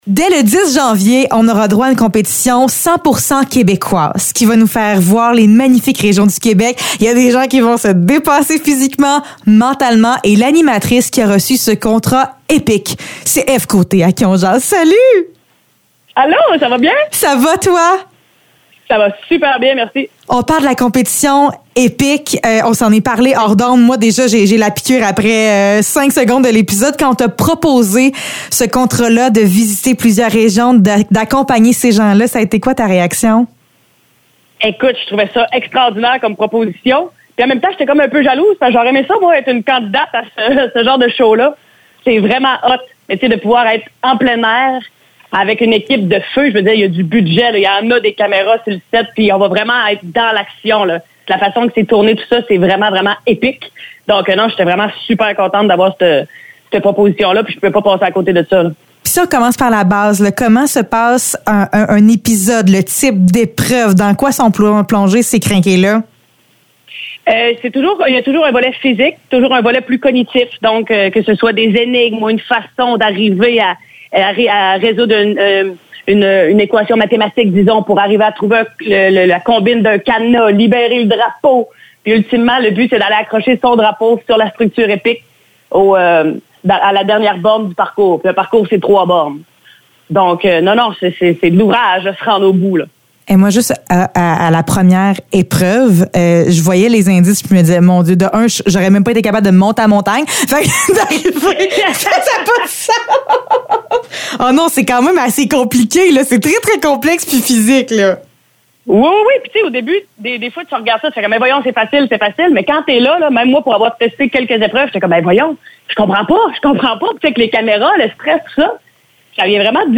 Entrevue avec Ève Côté pour l’émission ÉPIQUE